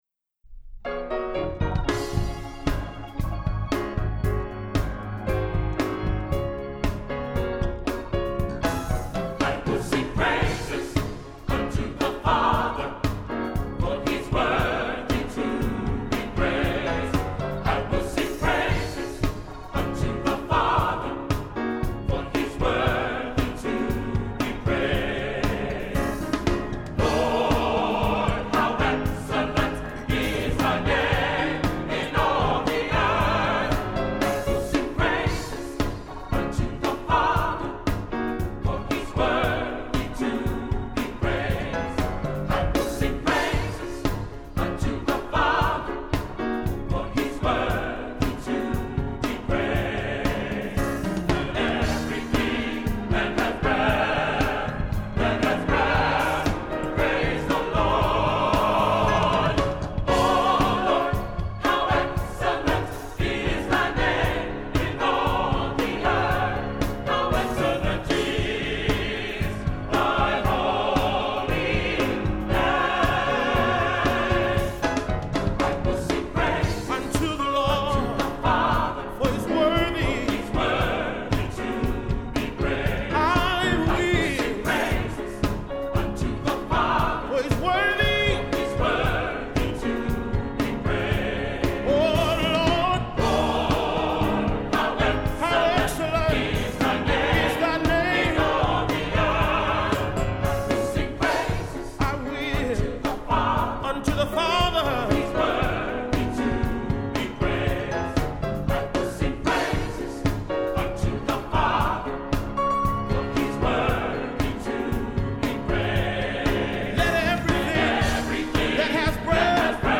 Genre-Stil-Form: geistlich ; Gospel ; Anthem Charakter des Stückes: energisch ; rhythmisch Chorgattung: SATB (4 gemischter Chor Stimmen )
Instrumente: Klavier (1)
Tonart(en): Des-Dur